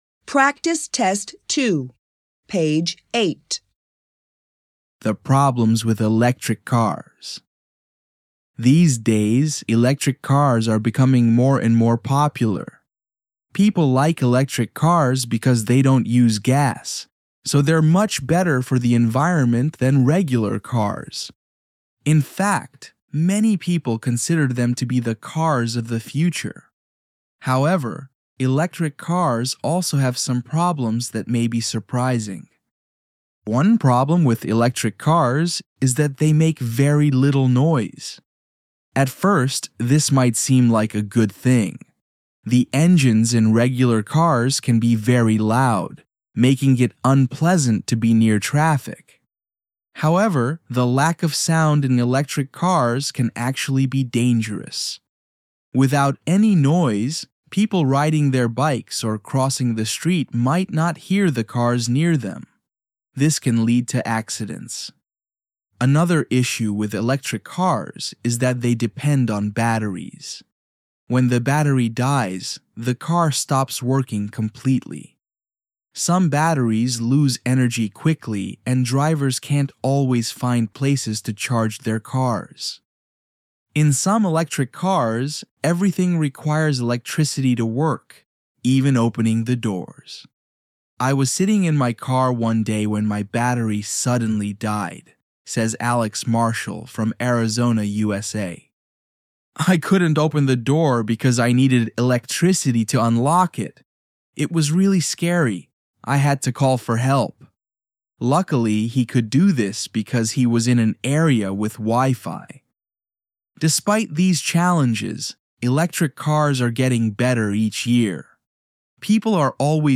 ECB Online offers you audio recordings of the reading texts from your coursebook to help you understand and enjoy your lessons.